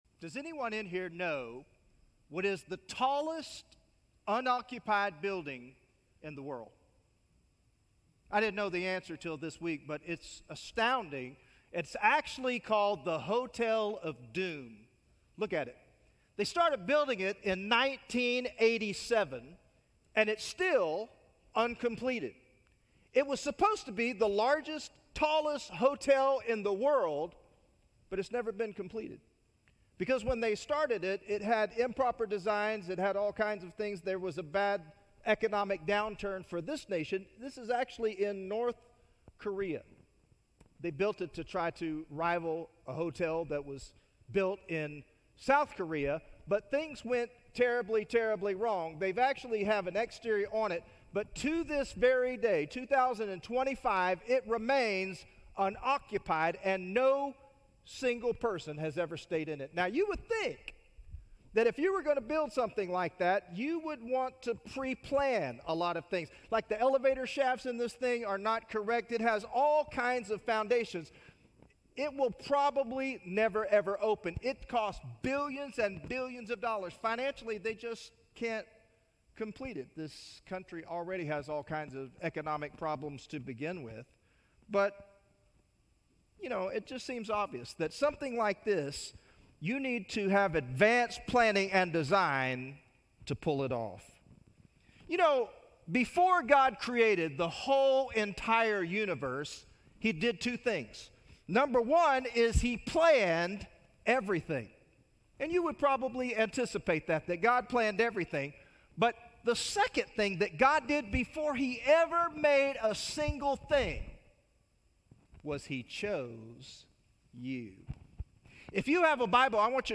Sermons - Sunnyvale FBC
From Series: "Guest Speaker"